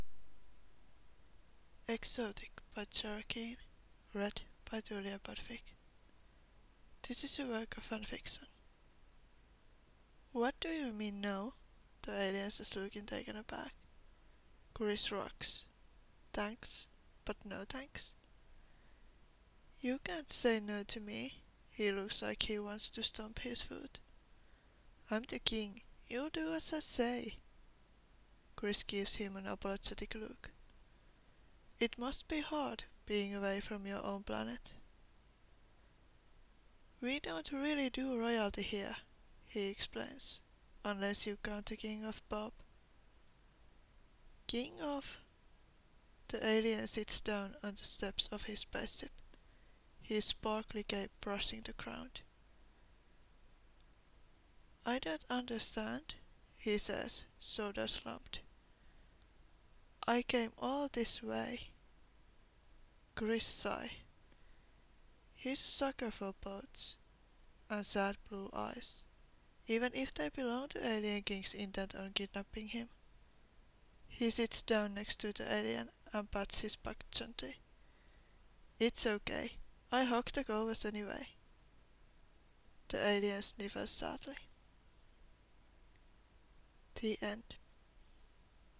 You might also notice that I'm not an native english speaker (or writer) and my accent made me laugh almost half an hour on the floor XD But hey, at least I'm used to make a fool of myself. Pronouncing and stuff is not perfect, but I would love some constructive criticisms, so I could learn from my mistakes and maybe made some more podfics in the future (: Thanks for giving me a chance.